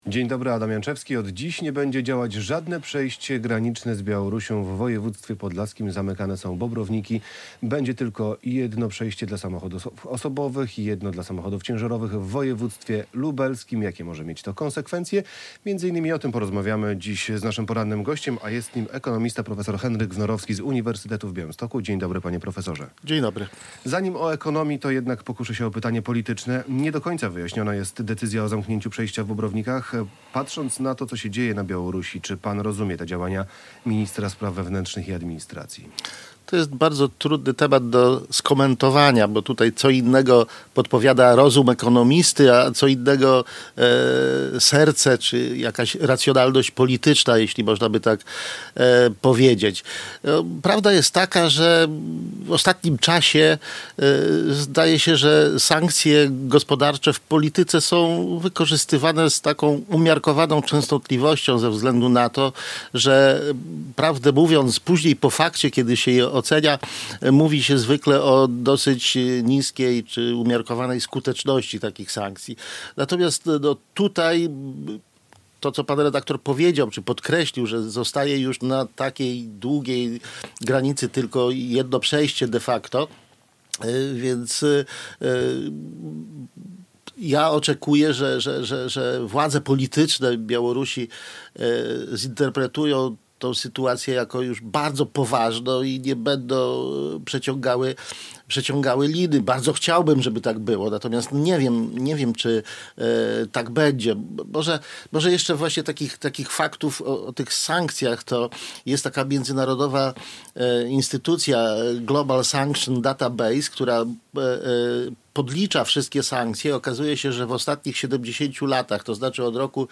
prof. Henryk Wnorowski [wideo] - ekonomista z UwB, członek Rady Polityki Pieniężnej